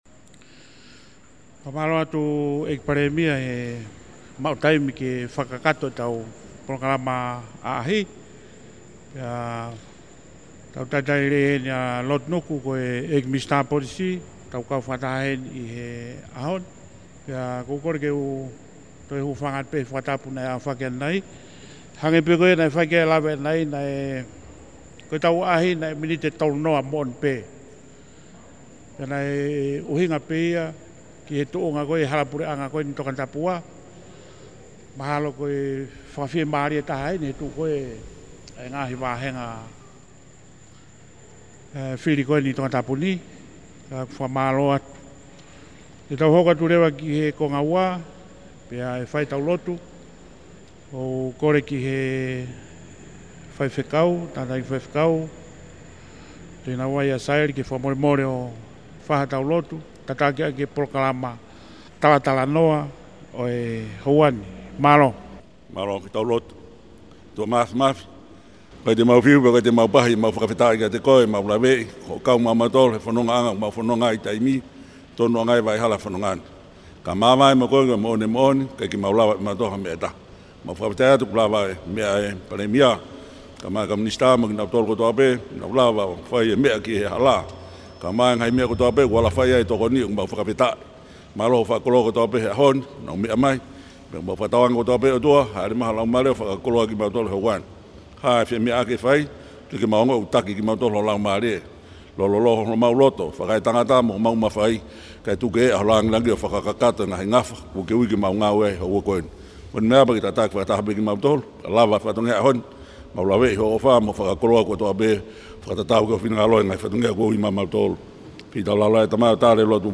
Audios of the Prime Minister’s meeting in Tongatapu 2 were provided by the Prime Minister’s office and transcribed and translated into English by Kaniva News.